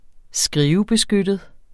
Udtale [ -beˌsgødəð ]